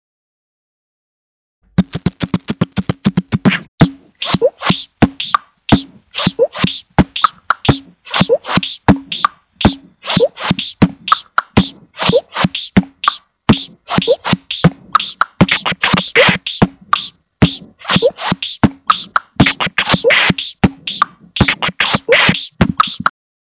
мой 1-ый битбокс
ну тыж использовал прогу...так неинтересно даже,"живой" битбокс и есть "живой" битбокс smile